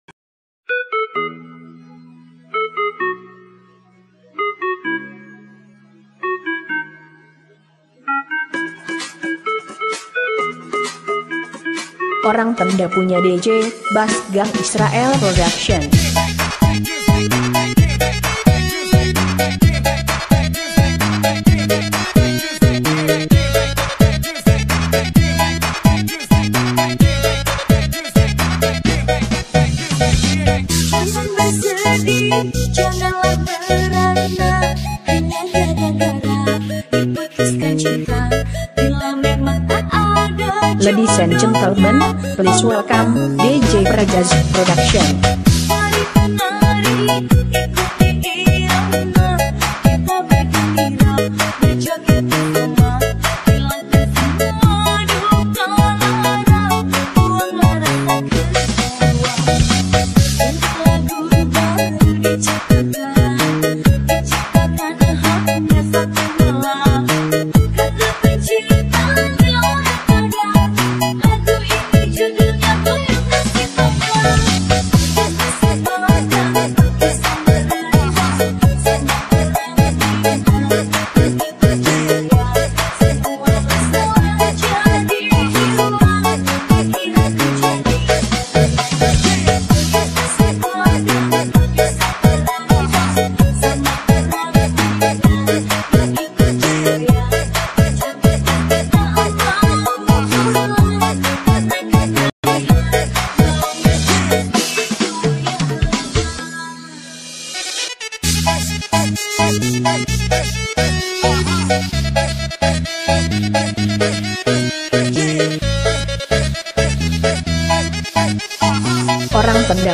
umumnya bergenre house dan remix